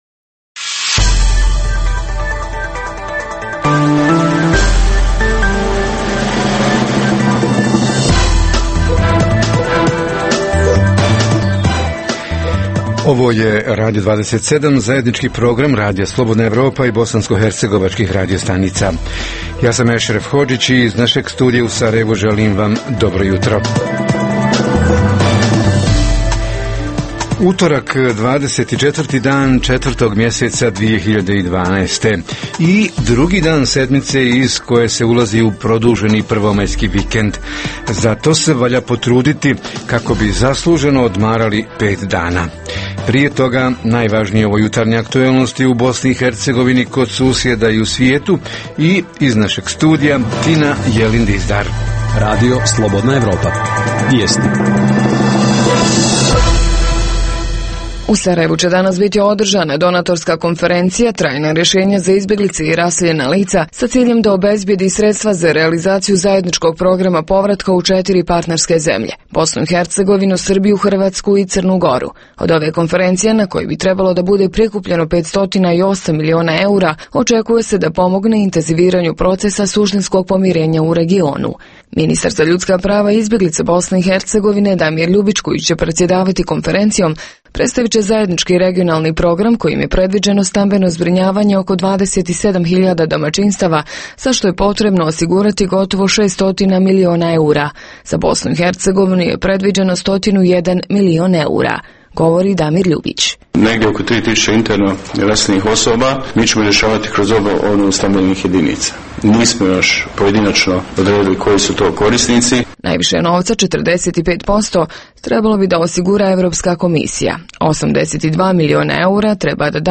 Tema Jutarnjeg programa: Borci i ratni vojni invalidi – kako su organizirani i koliko se efikasno bore za ostvarivanje prava boraca i ratnih vojnih invalida? Reporteri iz cijele BiH javljaju o najaktuelnijim događajima u njihovim sredinama.
Redovni sadržaji jutarnjeg programa za BiH su i vijesti i muzika.